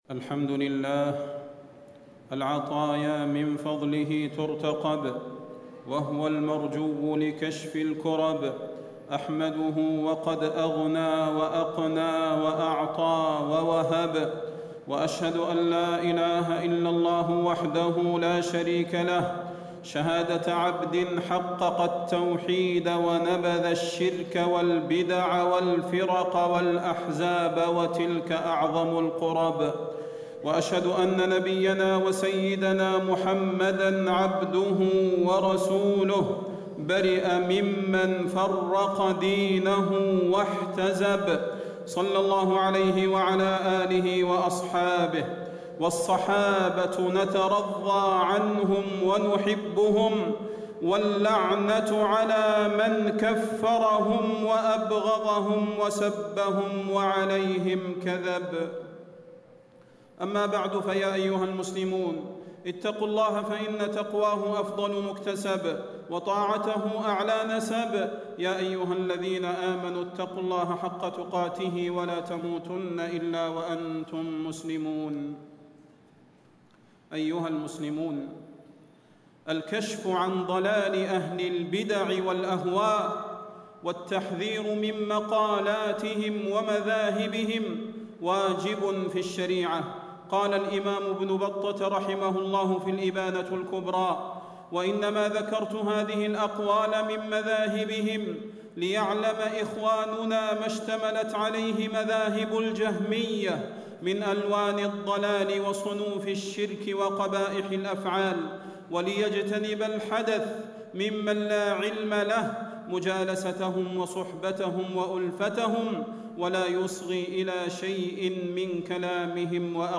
فضيلة الشيخ د. صلاح بن محمد البدير
تاريخ النشر ٢٩ شوال ١٤٣٦ هـ المكان: المسجد النبوي الشيخ: فضيلة الشيخ د. صلاح بن محمد البدير فضيلة الشيخ د. صلاح بن محمد البدير حقيقة الخوارج داعش